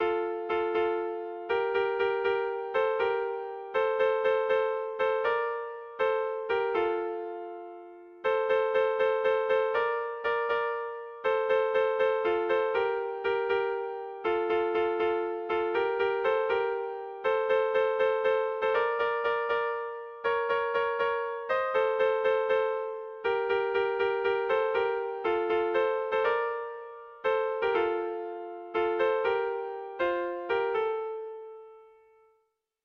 Zortzi puntukoa, berdinaren moldekoa
ABDEF